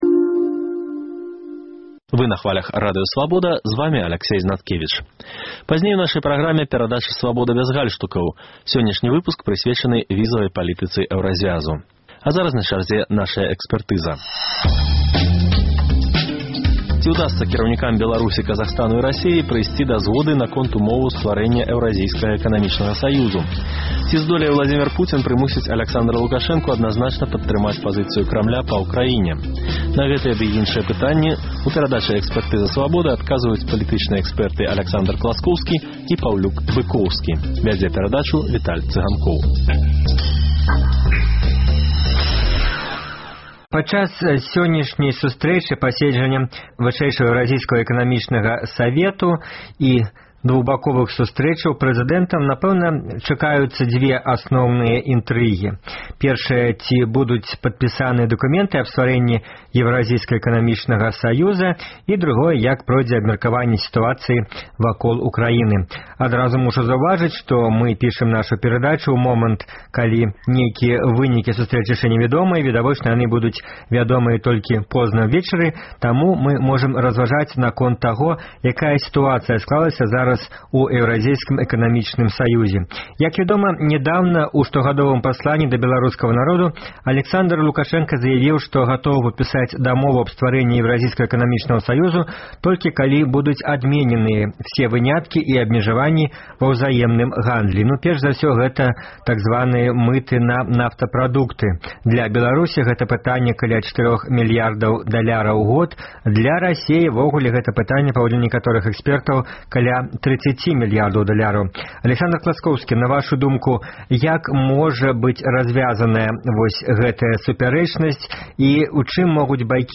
На гэтыя ды іншыя пытаньні ў перадачы адказваюць палітычныя экспэрты